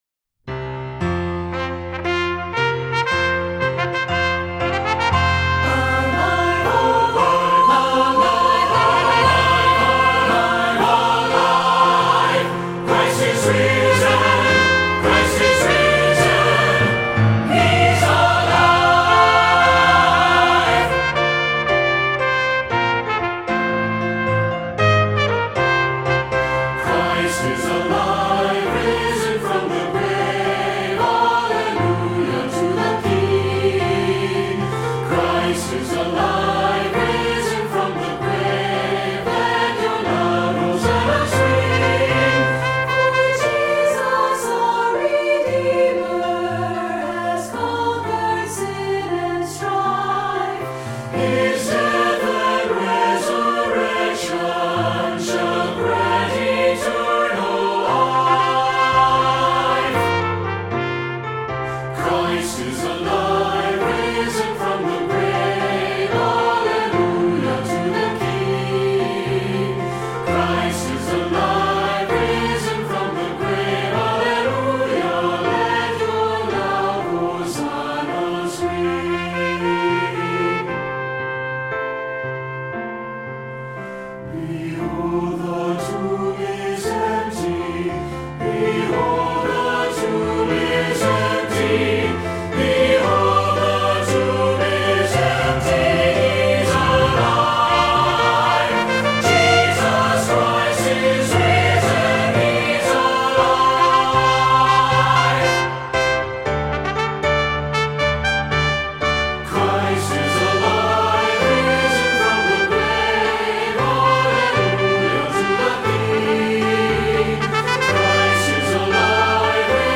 Voicing: SATB and Trumpet